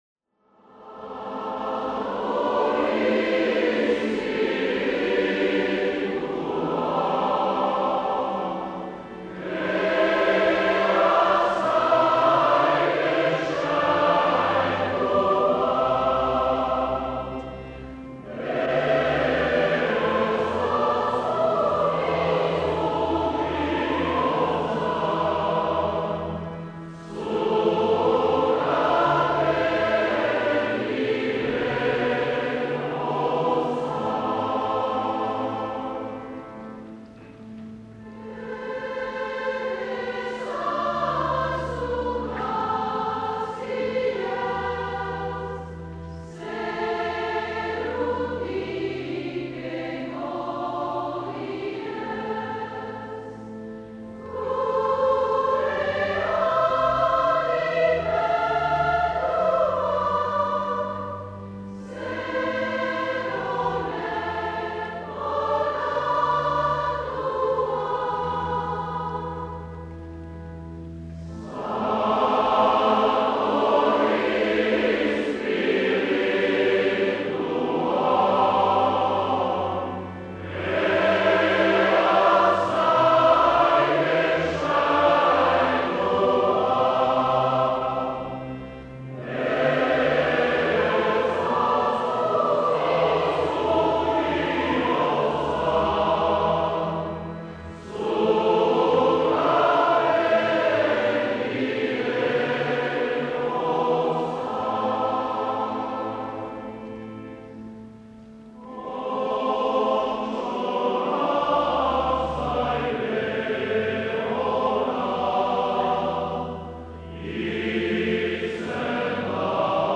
2020-05-31 Mendekoste A - Uztaritze
Igandetako Mezak Euskal irratietan